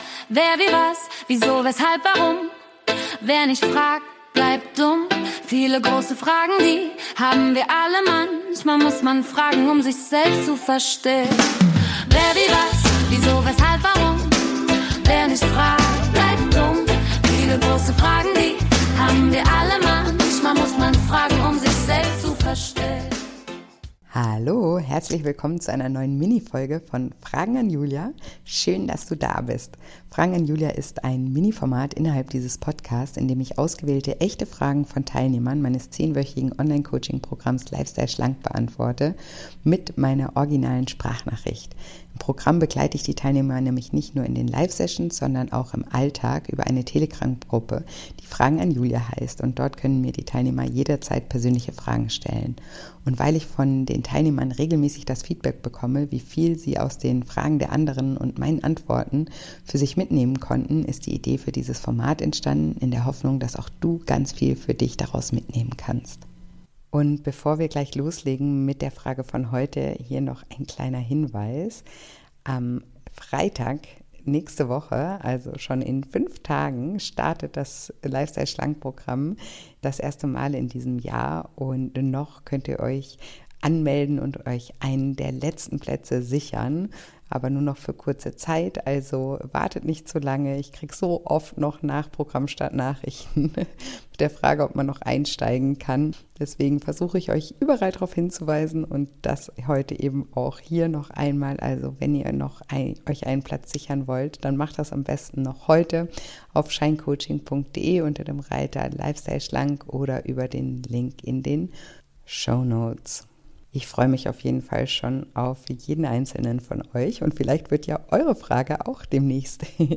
Bitte beachte, dass es sich dabei um originale Sprachnachrichten aus dem Coaching-Alltag handelt. Die Audioqualität ist daher nicht wie gewohnt – der Inhalt dafür umso authentischer.